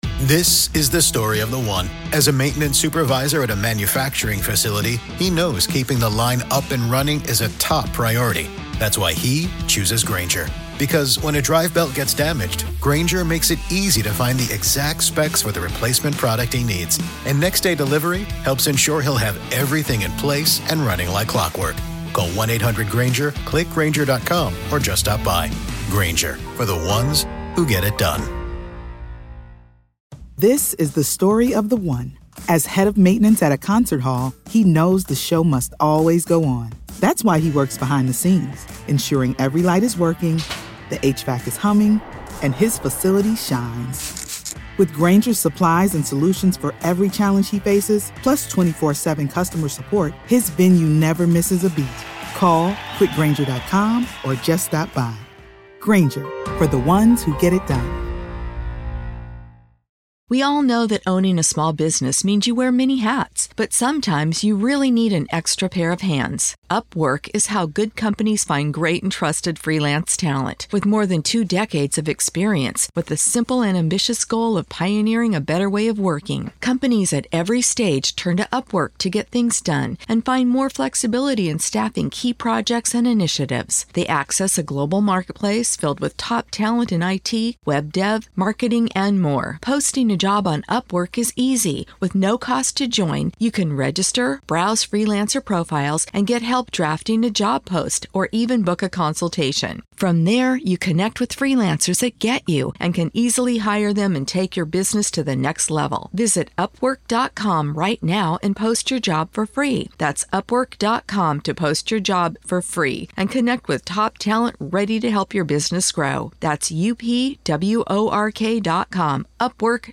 This is a raw, no-fluff, deeply informed conversation about power, legal strategy, and the high-stakes reality of defending the accused in one of the most disturbing murder cases in recent history.